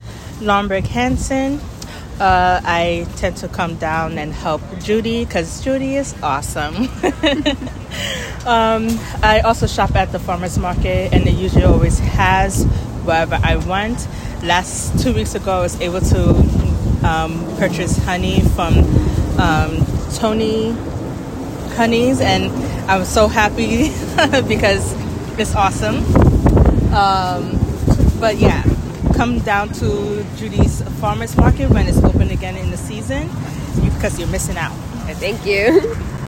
Listen to testimonials from our community members.